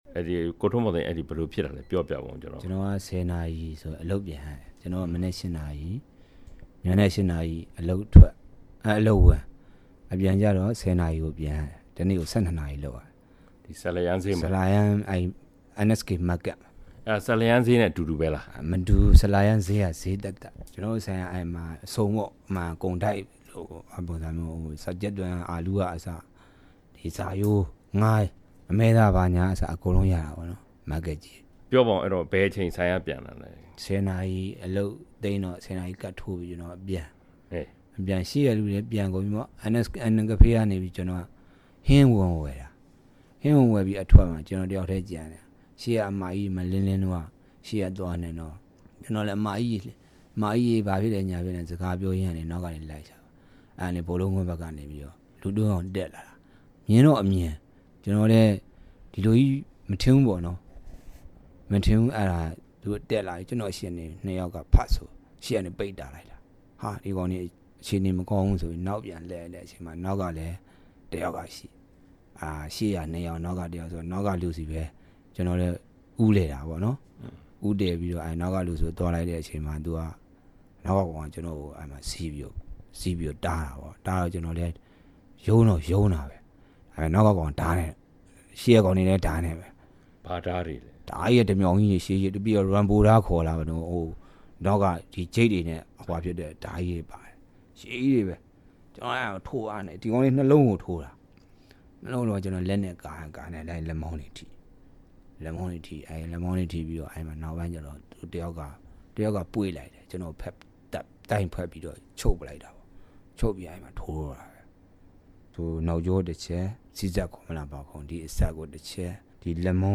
မလေးရှားမှာ အတိုက်ခိုက်ခံရပြီး အသက် မသေဘဲ လွတ်မြောက်ခဲ့သူနဲ့ တွေ့ဆုံမေးမြန်းချက်